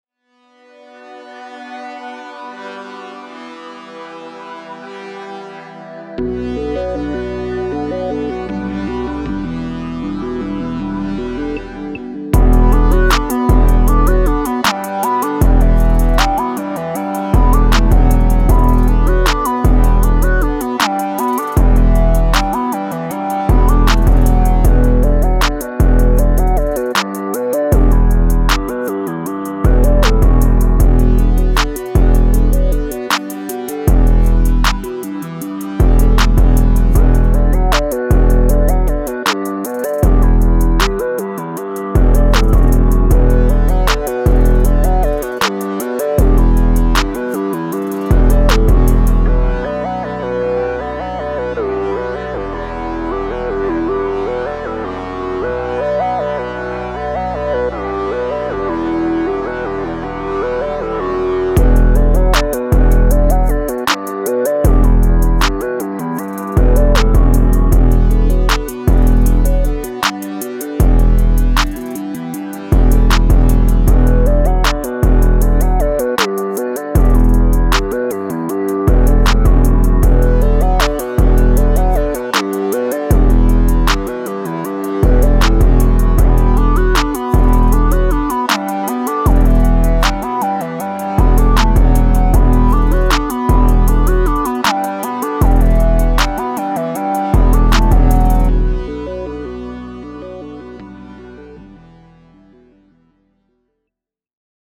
Cold, Dark, Energetic, Sexy
Drum, Heavy Bass, Piano, Strings